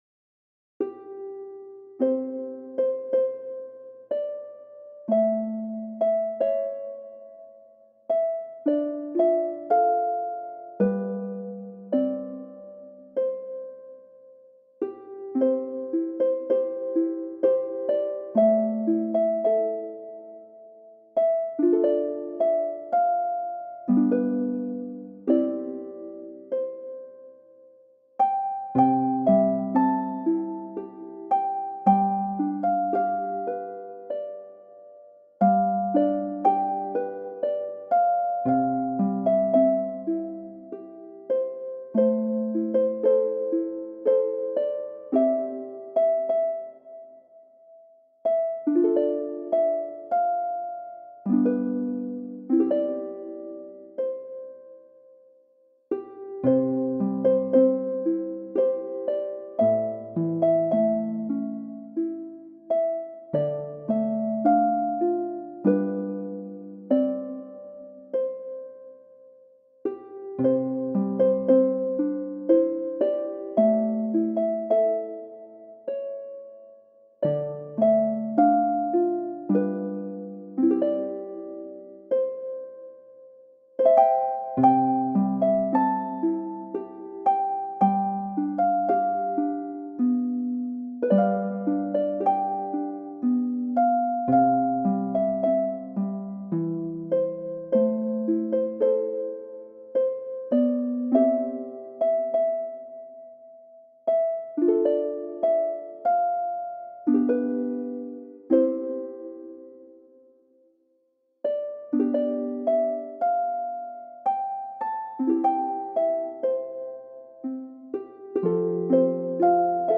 Musique de Noël relaxante : harpe douce et ambiance chaleureuse